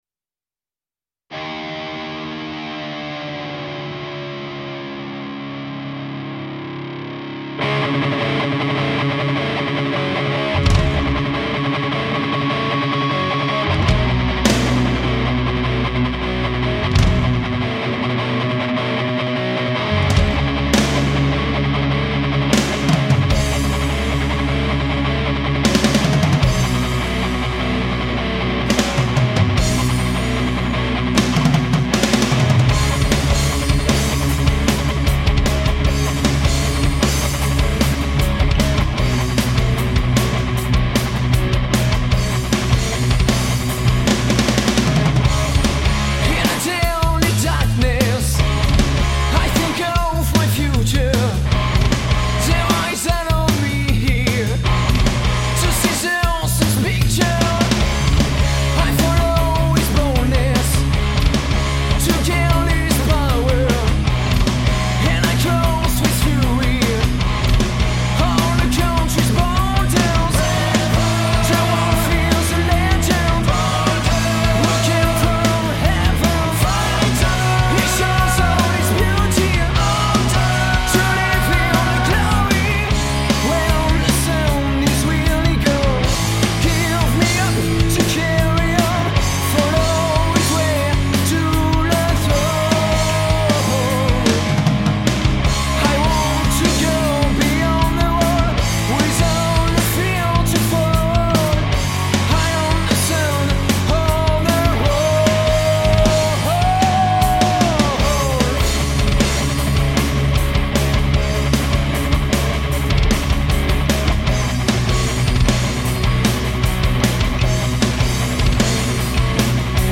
heavy metal France